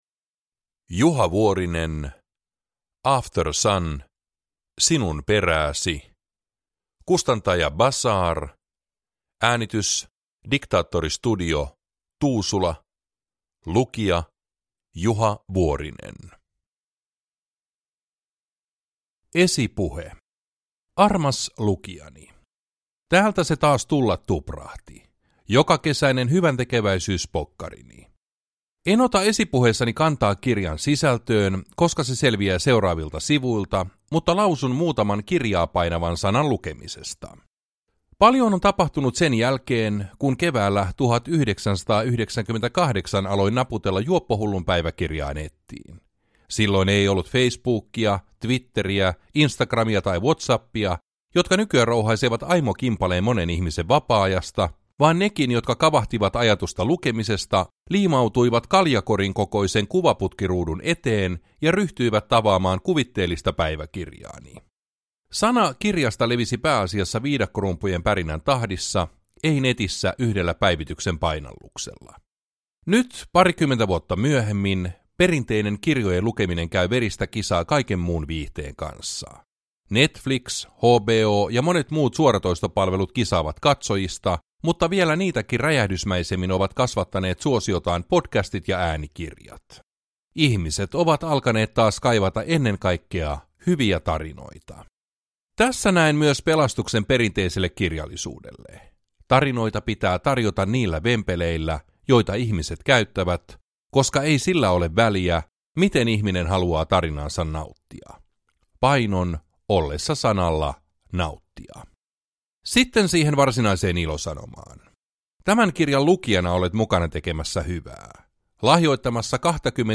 After Sun – sinun perääsi – Ljudbok – Laddas ner
Uppläsare: Juha Vuorinen